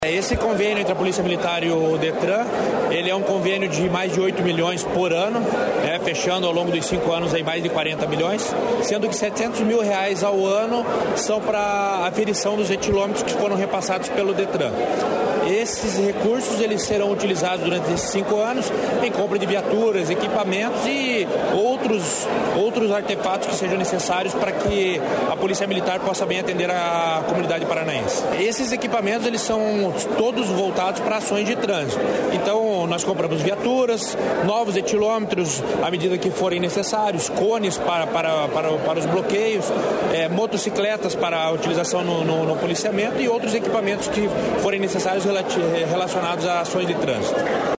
Sonora do comandante-geral da Polícia Militar, Jefferson Silva, sobre as ações de segurança no trânsito